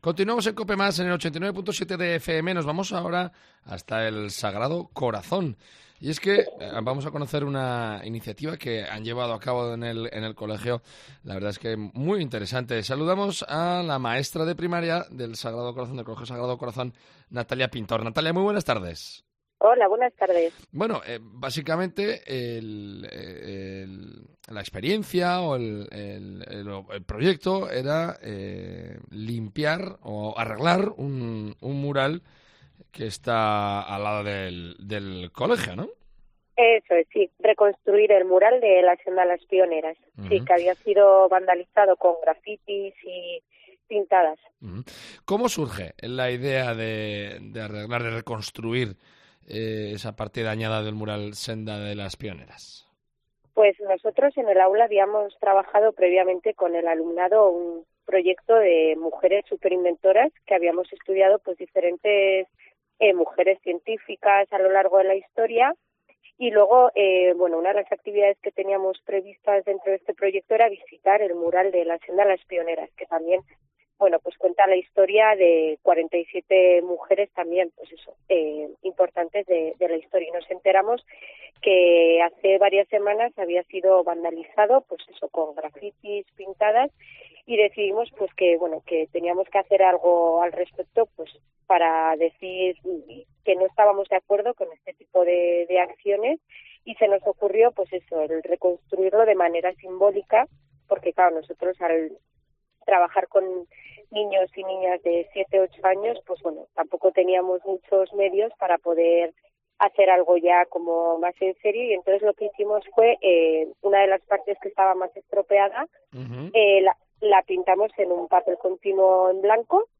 Pamplona